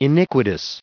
added pronounciation and merriam webster audio
1616_iniquitous.ogg